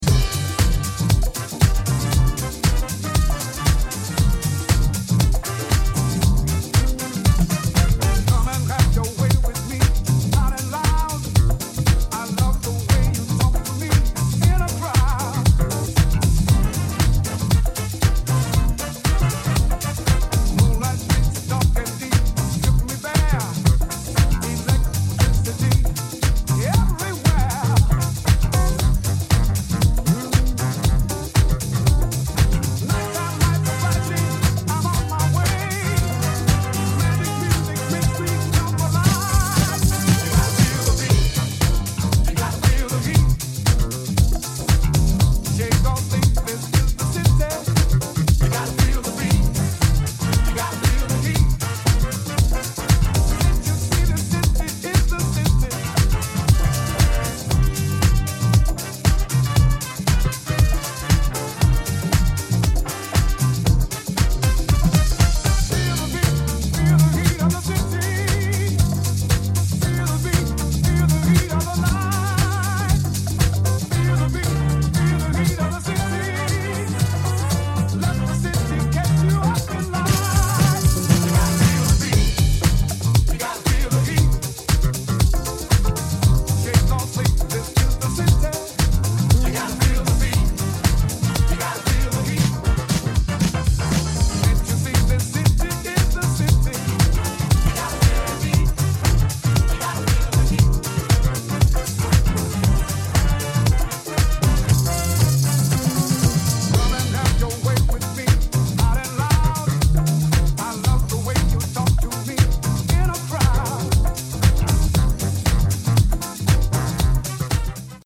Disco / Balearic Edit